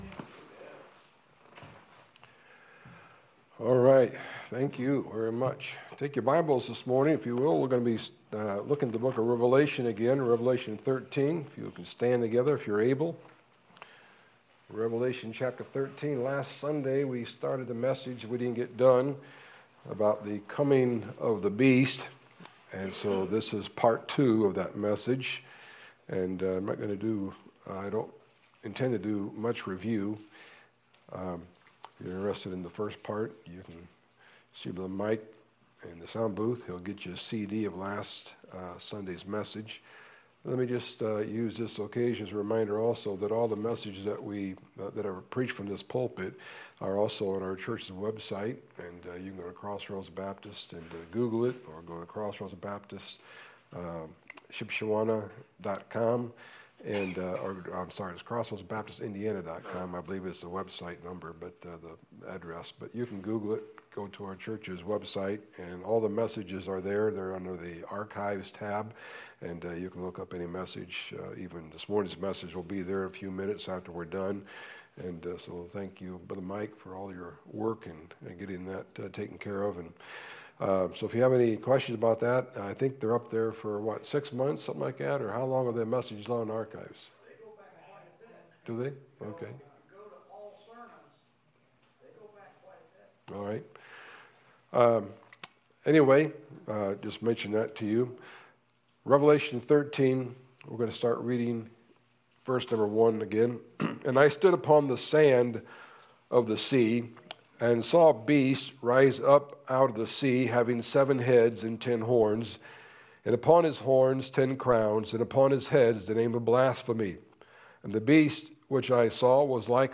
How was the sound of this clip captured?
Morning Service: The Beast Is Coming Pt. 2